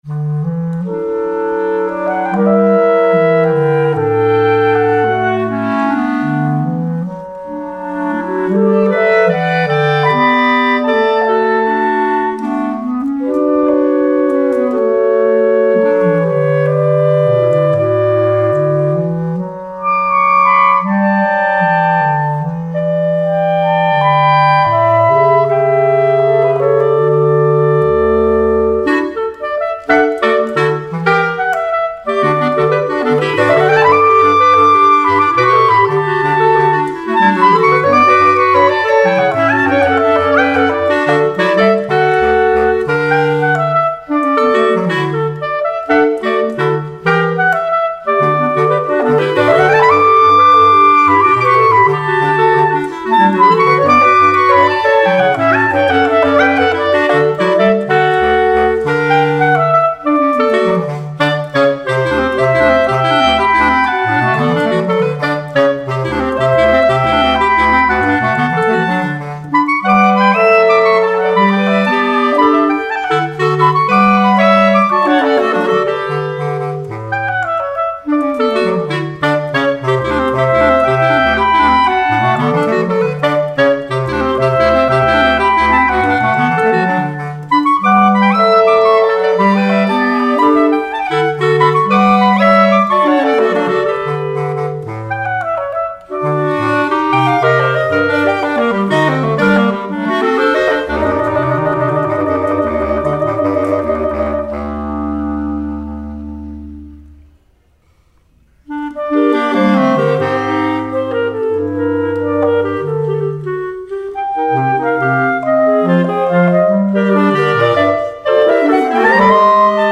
Bambuco